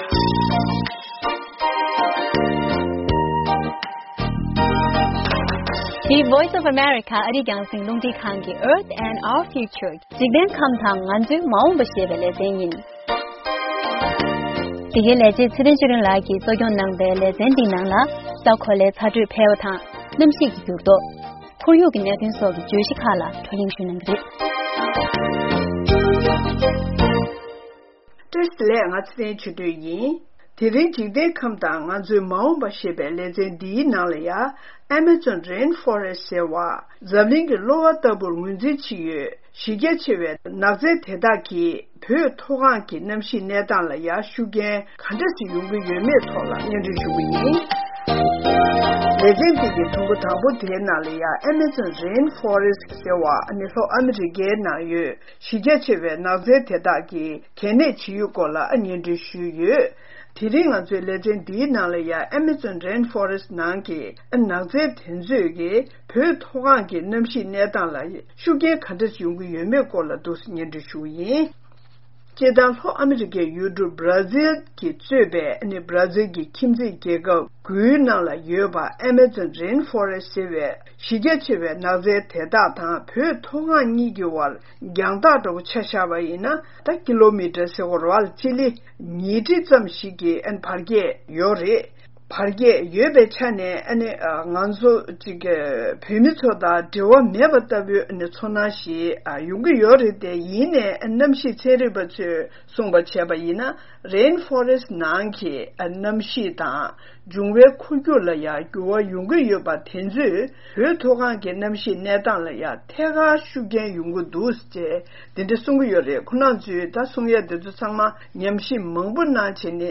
གནམ་གཤིས་ཚན་རིག་པ་ཚོས་ལྷོ་ཨ་མི་རི་ཀའི་ནང་ཡོད་ཨཱེ་མེ་རྫོང་ནགས་སེབ་མངའ་ཁུལ་གྱི་གནམ་གཤིས་གནས་སྟངས་དང་། བོད་མཐོ་སྒང་གི་གནམ་གཤིས་གཉིས་དབར་ཐད་ཀར་འབྲེལ་བ་ཡོད་པ་མ་ཟད་། བོད་མཐོ་སྒང་གི་ཚ་དྲོད་དང་། ཆར་ཆུ་བབས་རྒྱུན་ལ་ཡང་འབྲེལ་བ་ཡོད་པ་ཤེས་རྟོགས་བྱུང་ཡོད་ཅེས་འགྲེལ་བརྗོད་བྱེད་ཀྱི་ཡོད་པའི་སྐོར་ལ་གླེང་མོལ་བྱས་ཡོད།